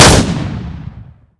sound / weapons / shotgun
weap_m1014_slmn_y1b.wav